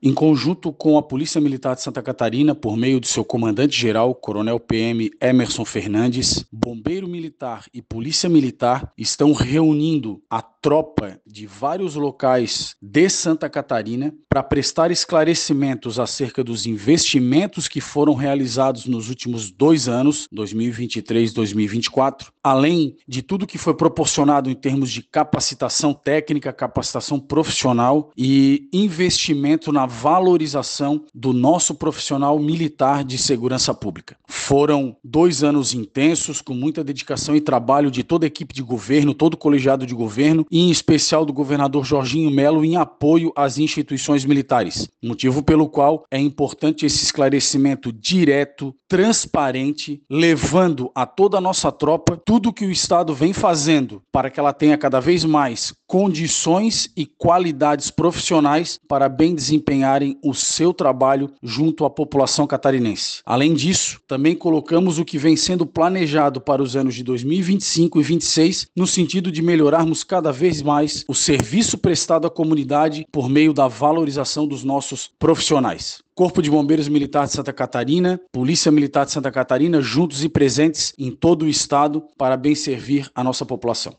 O comandante-geral do CBMSC, coronel Fabiano de Souza, reforçou o ineditismo e a relevância da iniciativa:
SECOM-Sonora-Comandante-Geral-CBMSC.mp3